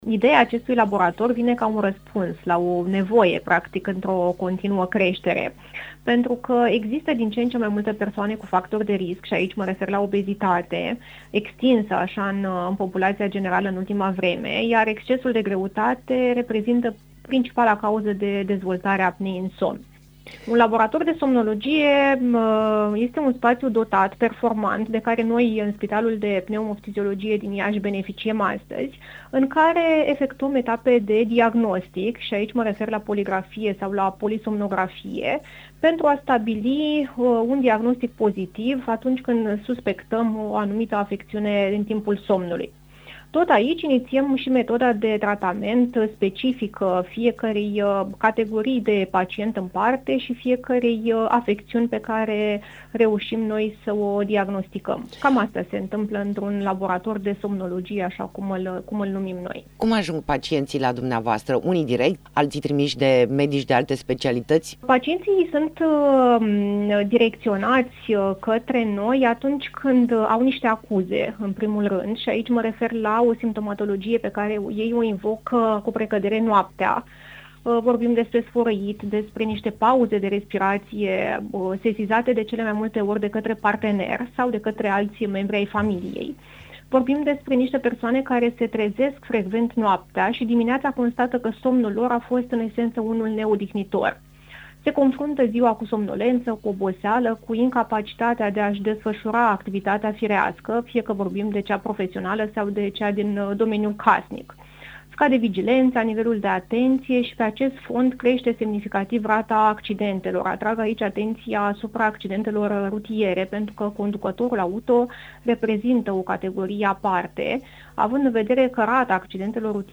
Interviu-somnologia.mp3